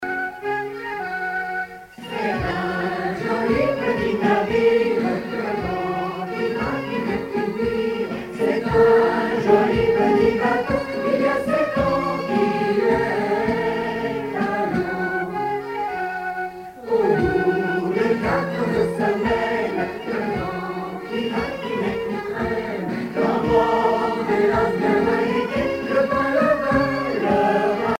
laisse
Pièce musicale éditée